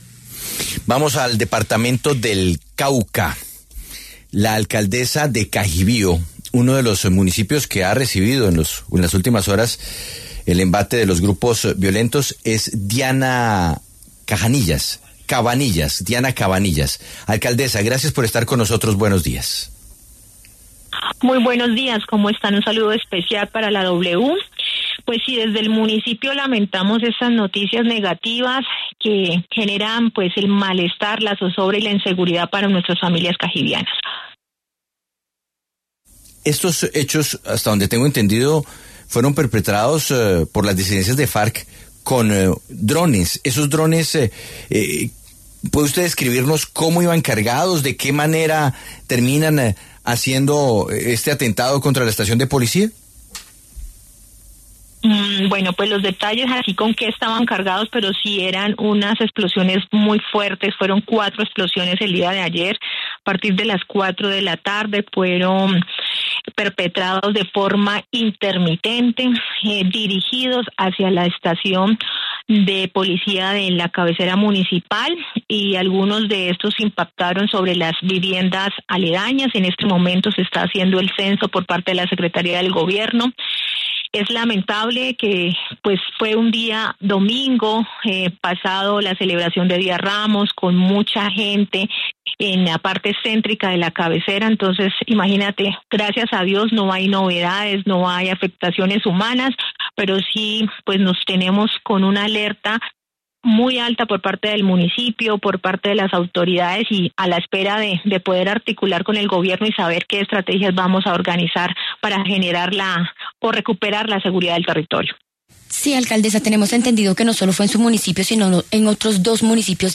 Durante una entrevista con La W, la alcaldesa de Cajibío, Diana Cabanillas, confirmó que el pasado domingo su municipio fue blanco de un ataque con explosivos lanzados desde drones contra la estación de Policía de la cabecera municipal.